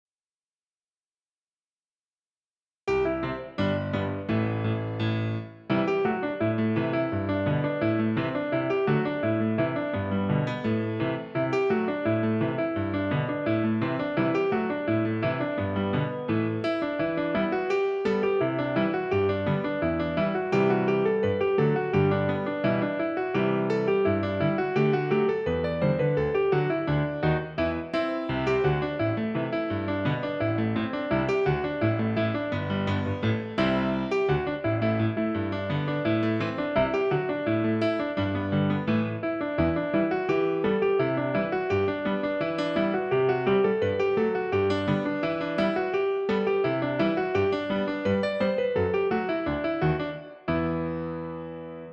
Piano accompaniment
Genre Celtic and Gaelic
Tempo 85
Rhythm Reel
Meter 4/4